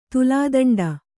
♪ tulādaṇḍa